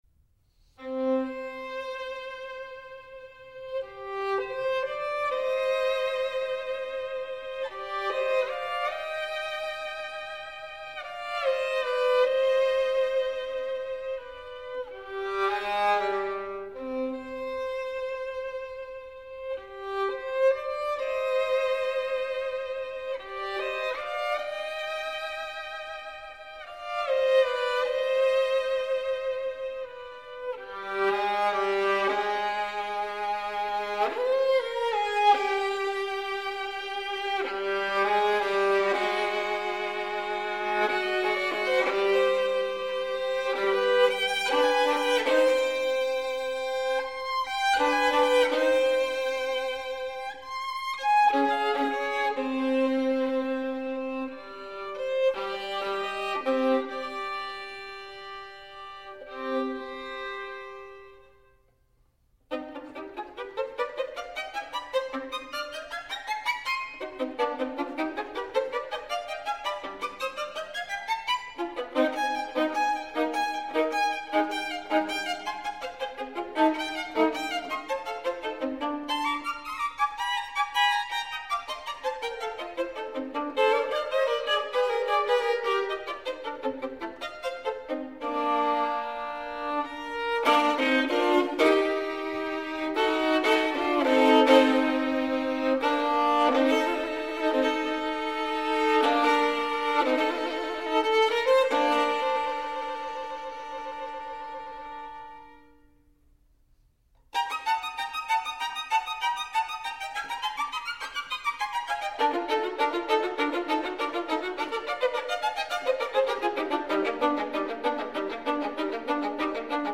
Chi lo ha visto almeno una volta cimentarsi nell’assurdo tour de force dell’esecuzione integrale dal vivo, si ricorderà la sorprendente facilità con cui anche nel corso della più complessa pirotecnia, sapeva cavare il profilo di un arco melodico cantabile, con tutti i suoi accenti e le sue dinamiche.
Per chi fosse interessato a un confronto, riporto qui il bellissimo e misterioso inizio del Capriccio n. 4 in Do minore (Maestoso), prima nell’interpretazione di Salvatore Accardo (incisione del 1978) e poi in quella di Zehetmair. La differenza, in termini sia di velocità sia di spirtito, è abbastanza evidente.